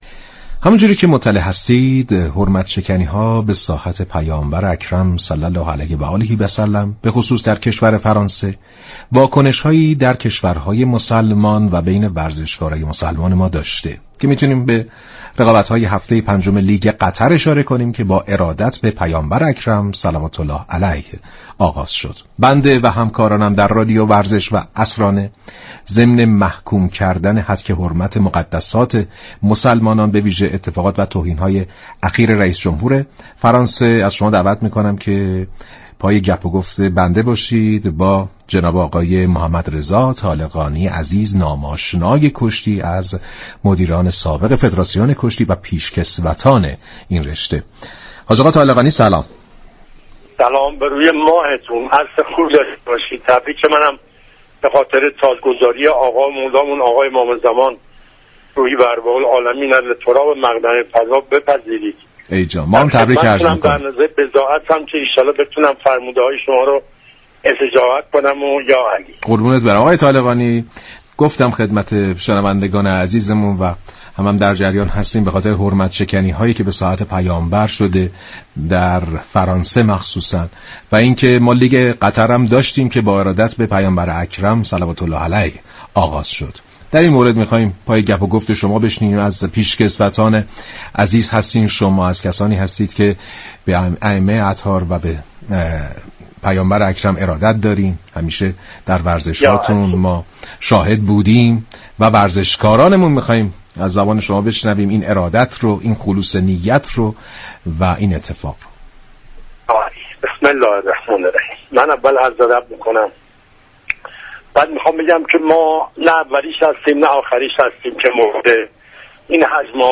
شما می توانید از طریق فایل صوتی پیوست شنونده این گفتگو با رادیو ورزش باشید.